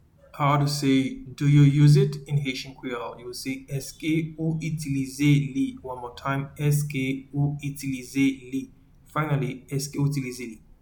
Pronunciation:
Do-you-use-it-in-Haitian-Creole-Eske-ou-itilize-li.mp3